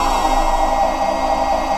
ATMOPAD17.wav